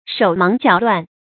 shǒu máng jiǎo luàn
手忙脚乱发音
成语注音ㄕㄡˇ ㄇㄤˊ ㄐㄧㄠˇ ㄌㄨㄢˋ